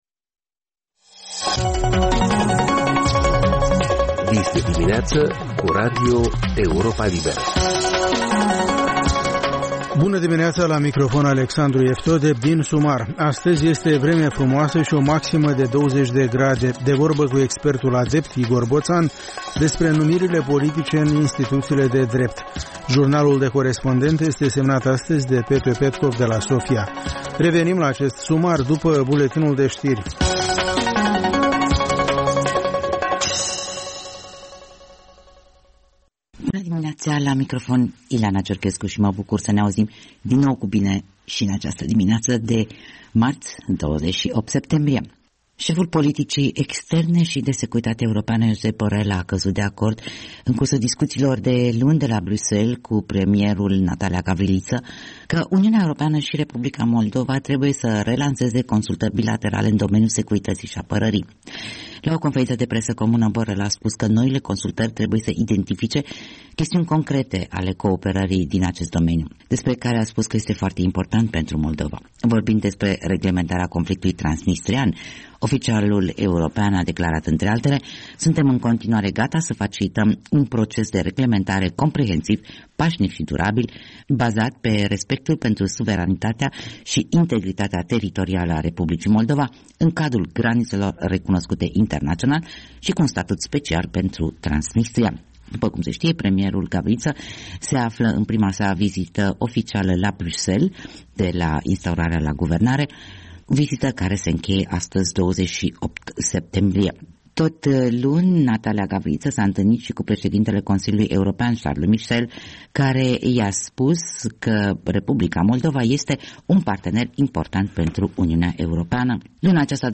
Ştiri, informaţii, interviuri, corespondenţe.